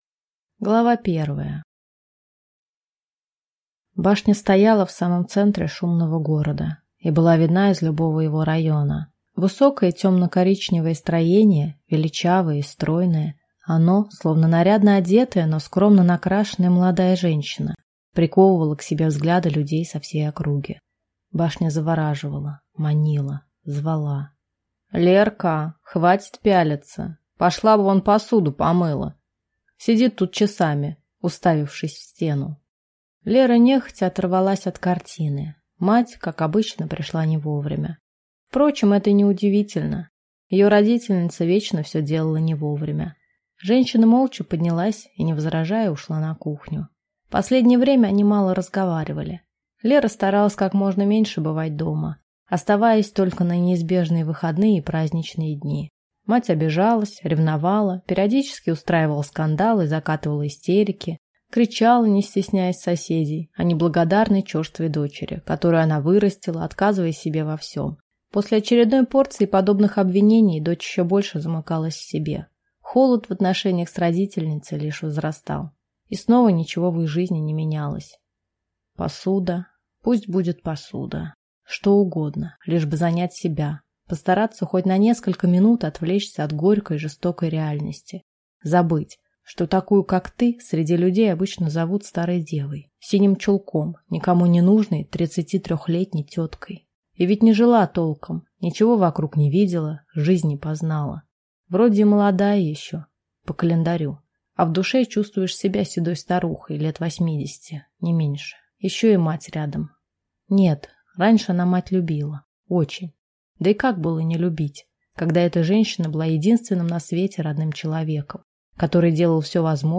Аудиокнига Башня. Путь к любви | Библиотека аудиокниг